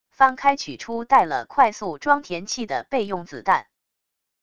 翻开取出带了快速装填器的备用子弹wav音频